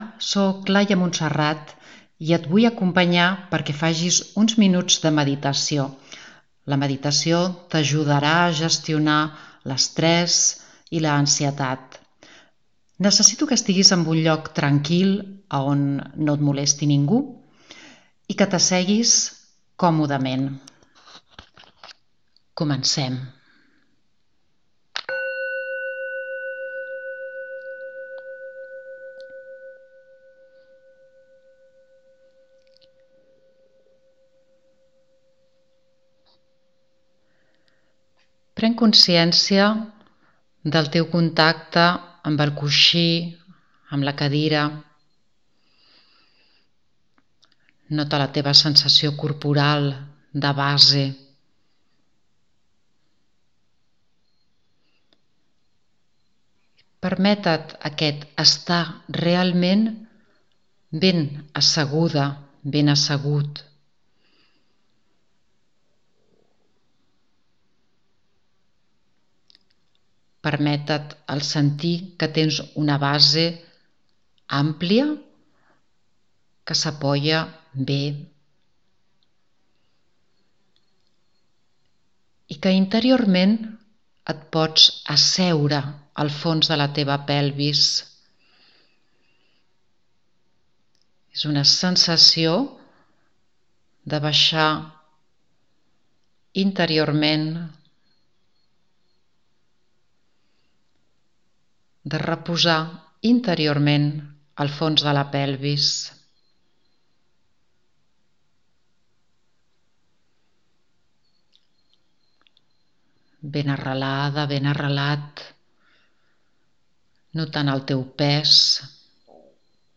Aquí hi tens un àudio que t’acompanyarà durant uns minuts, per què puguis viure una experiència de meditació bàsica.